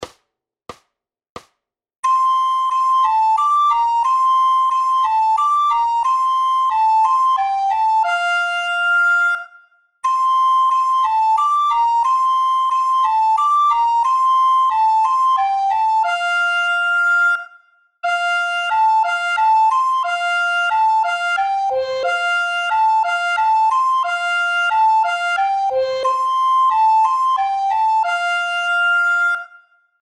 Hudební žánr Vánoční písně, koledy